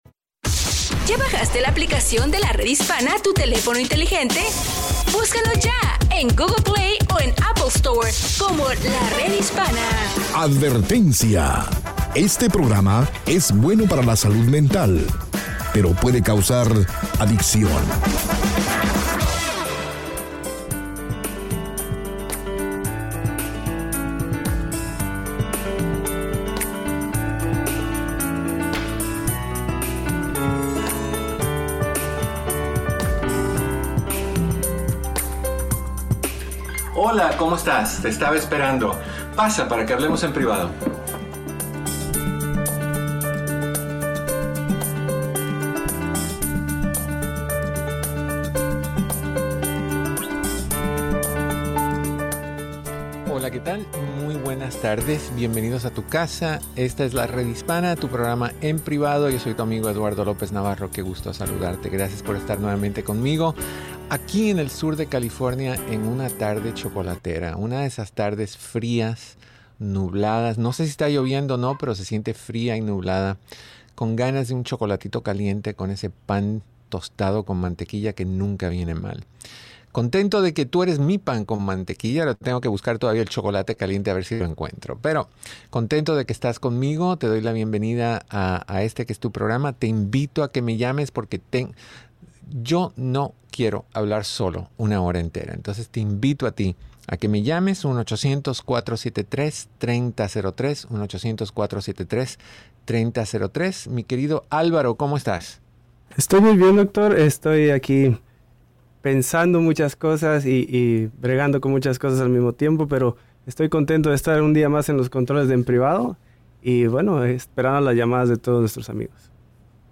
Escucha el programa de radio EN PRIVADO, de Lunes a Viernes a las 2 P.M. hora del Pacífico, 4 P.M. hora Central y 5 P.M. hora del Este por La Red Hispana y todas sus afiliadas.